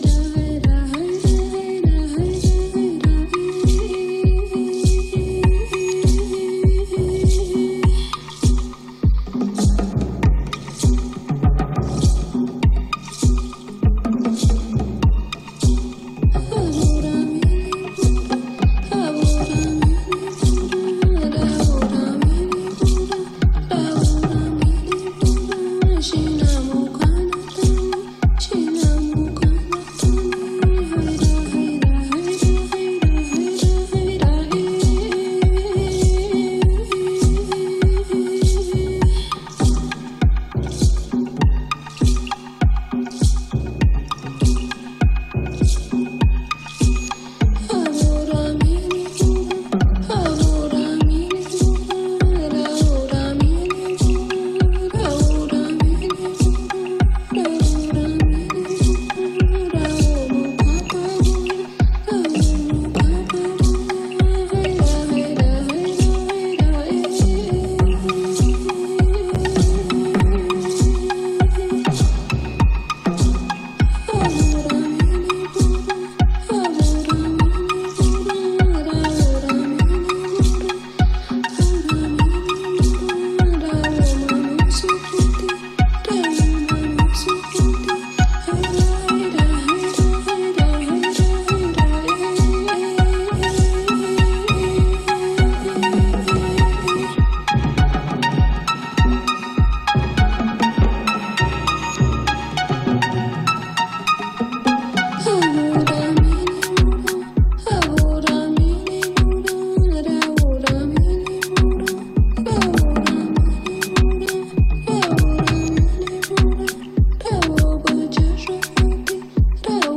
Diretta con una compagna di Mi cuerpo es mio dal minuto 6.30